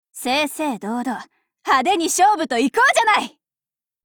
Cv-30512_warcry.mp3